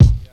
• Old School Phat Hip-Hop Kick Single Hit C Key 14.wav
Royality free bass drum sample tuned to the C note. Loudest frequency: 174Hz
old-school-phat-hip-hop-kick-single-hit-c-key-14-vKw.wav